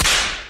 whipcrack.wav